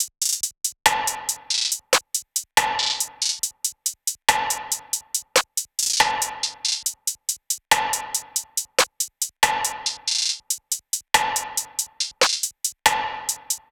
SOUTHSIDE_beat_loop_toast_top_140.wav